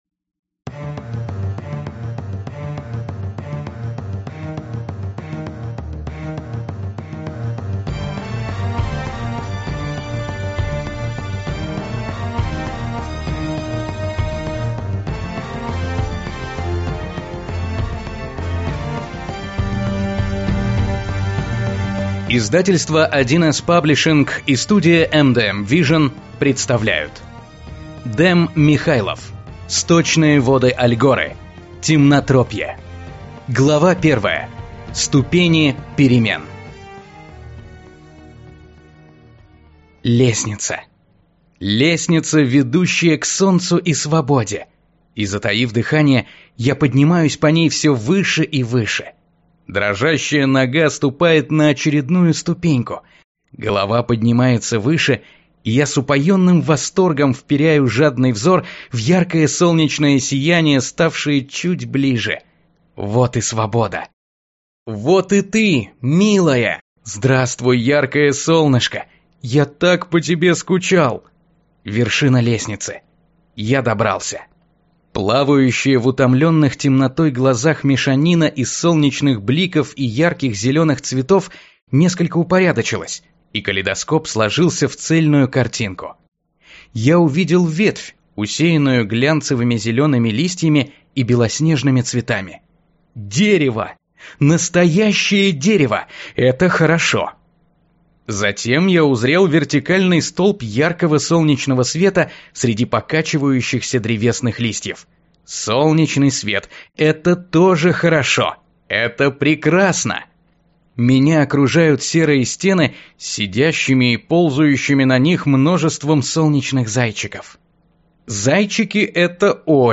Аудиокнига Темнотропье - купить, скачать и слушать онлайн | КнигоПоиск